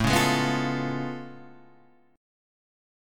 A Augmented Major 9th